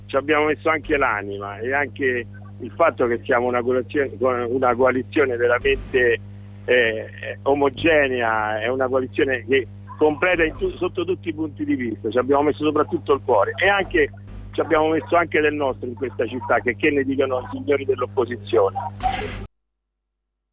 Ecco il commento a caldo, fatto durante i festeggiamenti, del sindaco Antonio Terra.